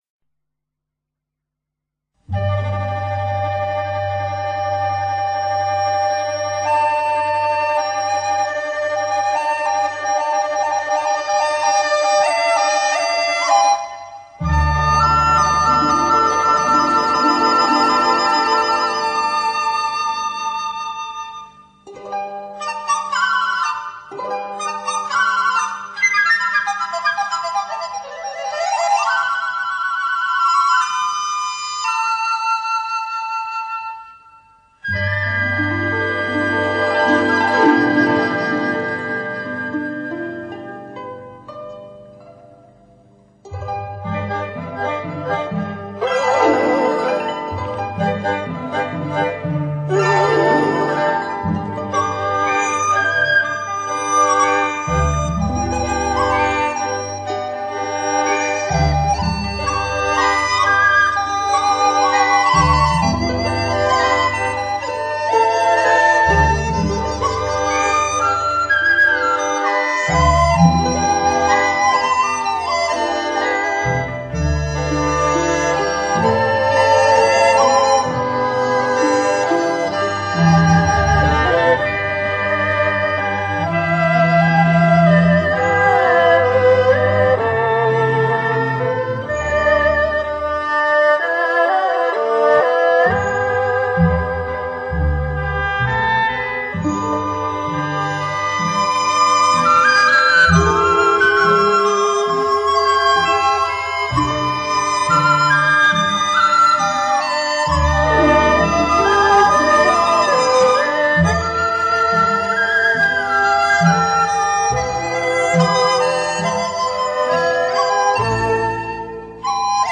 悠扬甜美的旋律里，不是掺入欢声笑语，时而又高亢激越，令人陶醉。